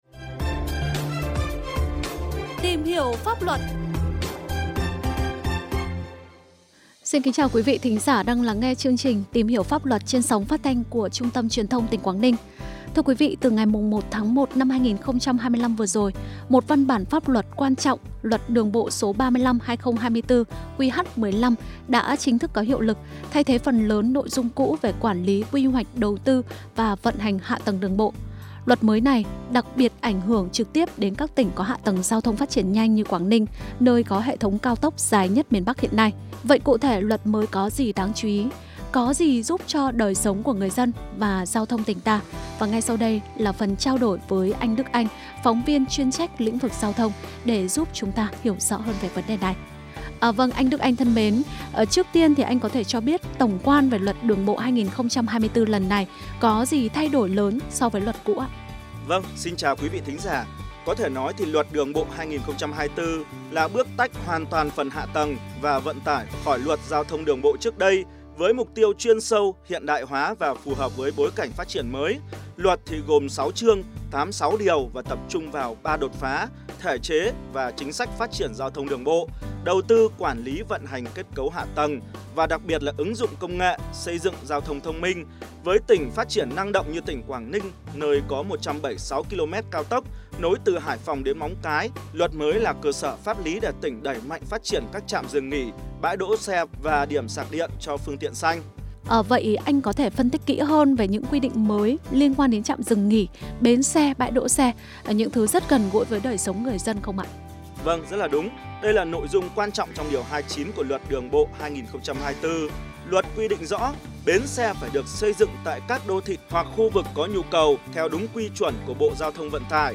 Nghe phóng sự phát thanh ” Một số điểm mới của Luật Trật tự, an toàn giao thông đường bộ 2024″